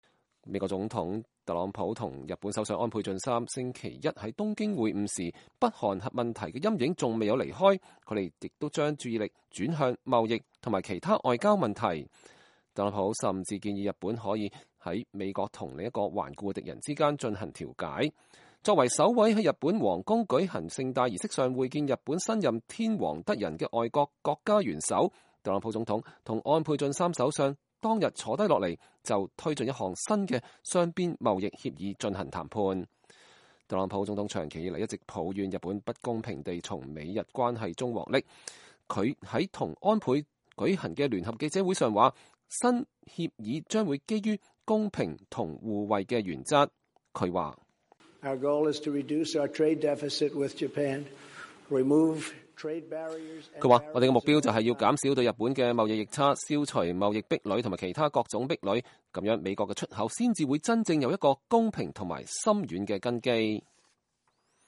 特朗普和安倍晉三在東京舉行聯合記者會。